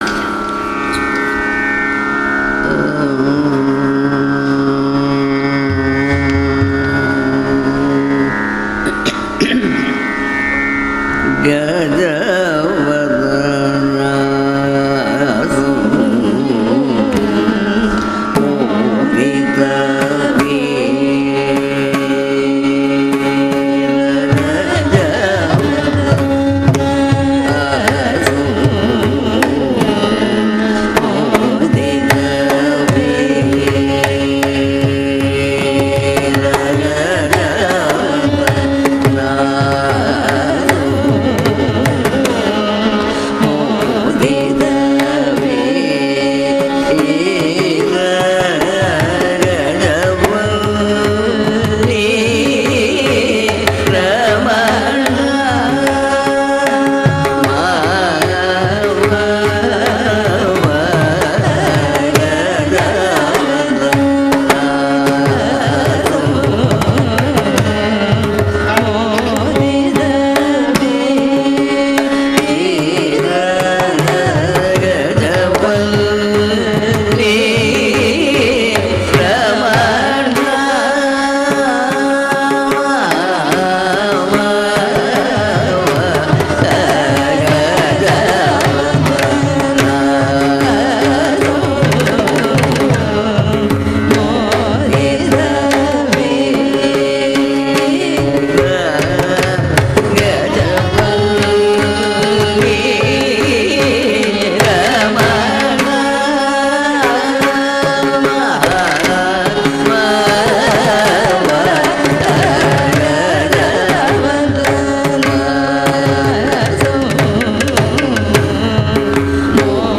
during a thematic concert at Thiruvananthapuram